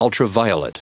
Prononciation du mot ultraviolet en anglais (fichier audio)
Prononciation du mot : ultraviolet